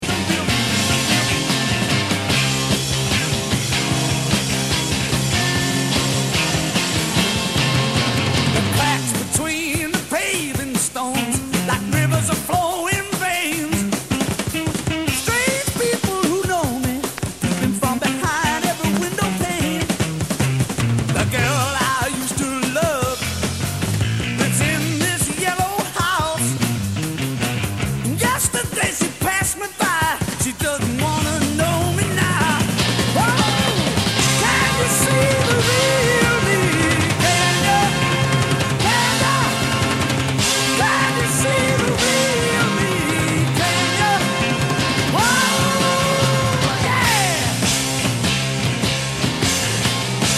My Favorite Songs with Notable Bass Guitar Performances
one of the ultimate lead bass performances